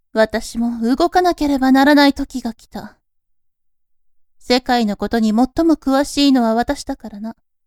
ボイス
性別：女